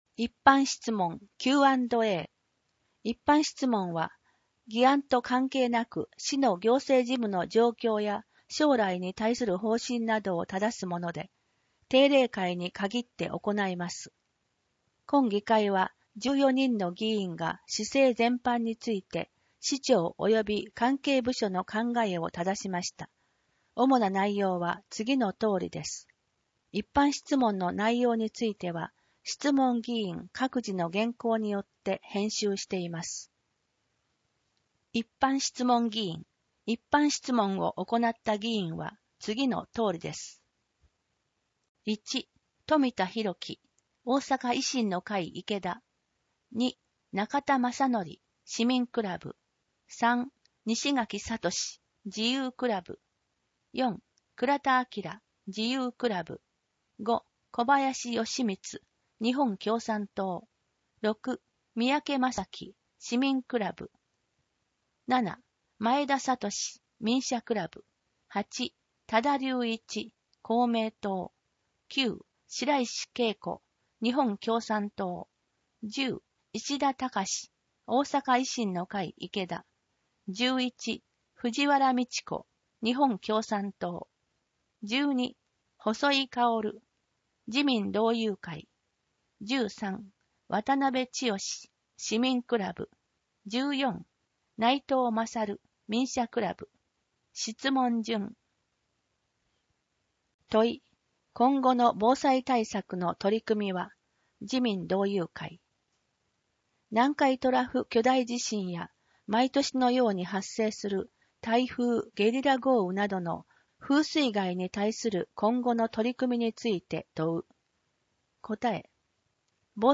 声の市議会だより